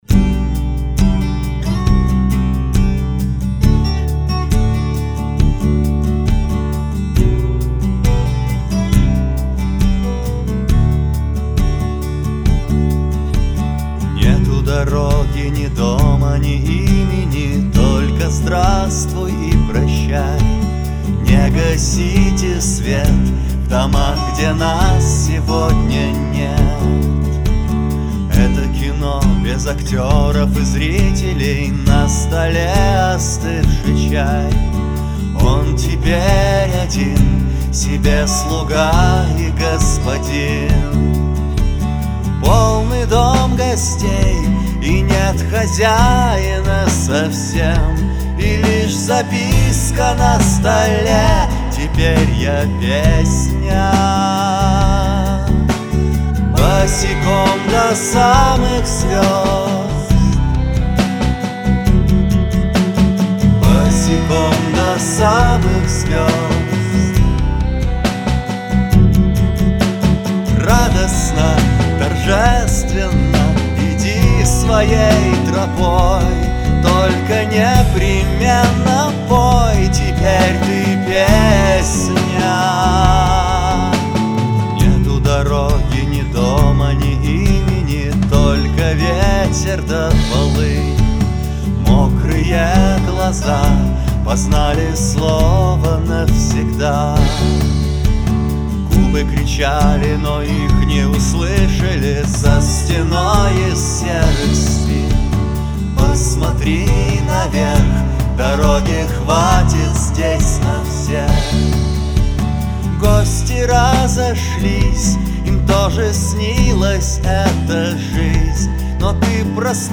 Альбом записан на домашней студии.